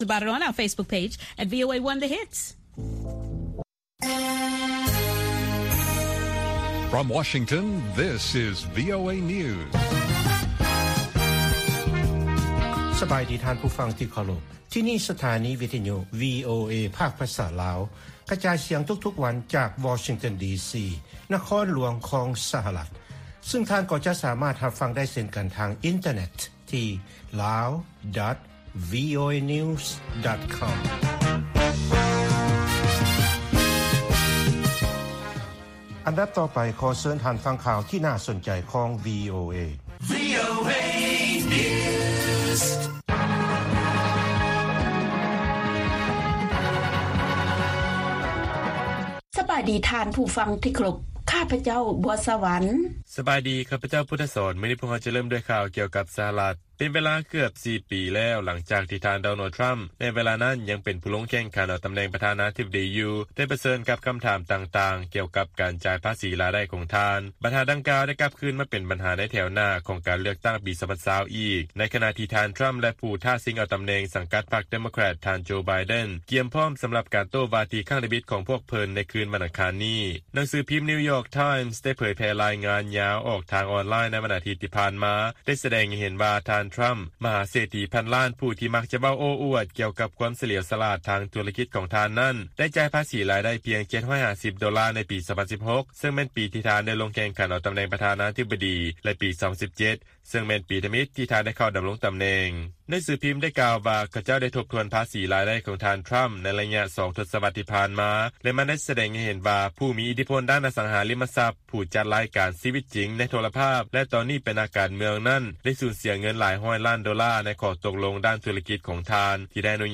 ລາຍການກະຈາຍສຽງຂອງວີໂອເອ ລາວ
ວີໂອເອພາກພາສາລາວ ກະຈາຍສຽງທຸກໆວັນ. ຫົວຂໍ້ຂ່າວສໍາຄັນໃນມື້ນີ້ມີ: 1)ລັດໂອຮາຍໂອ ພ້ອມແລ້ວ ຈະຟັງການໂຕ້ວາທີ ເທື່ອທຳອິດ ຂອງຜູ້ສະໝັກ ປະທານາທິບໍດີ. 2) ບັນດານັກຊ່ຽວຊານເຕືອນ ກ່ຽວກັບການໃຊ້ ວັກຊິນກັນໂຄວິດ-19 ແບບສຸກເສີນຂອງຈີນ . 3) ນັກສັງເກດການ ກ່າວວ່າ ການປະຕິເສດຂອງຈີນ ບໍ່ຮັບຮູ້ເສັ້ນແບ່ງເຂດແດນ ຢູ່ຊ່ອງແຄບໄຕ້ຫວັນ ມີແຕ່ຈະສ້າງຄວາມເຄັ່ງຕຶງ ທີ່ບໍ່ຈຳເປັນແລະຂ່າວສໍາຄັນອື່ນໆອີກ.